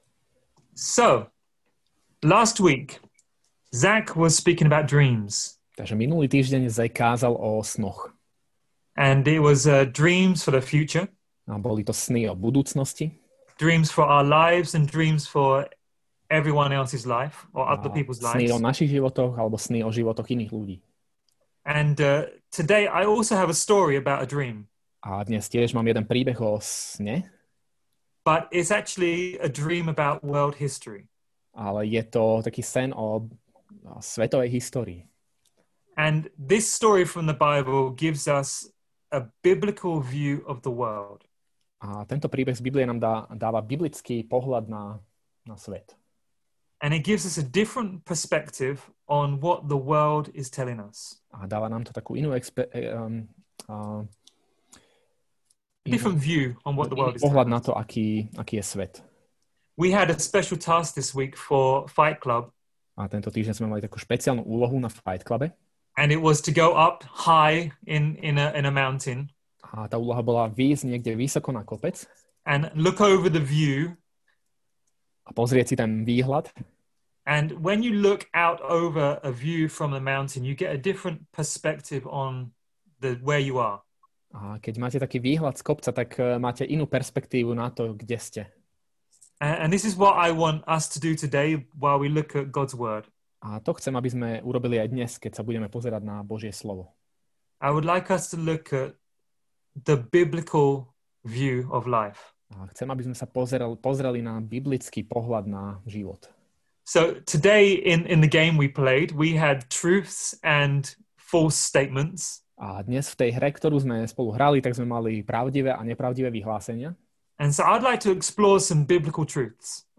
Nahrávka kázne Kresťanského centra Nový začiatok z 24. septembra 2021